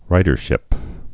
(rīdər-shĭp)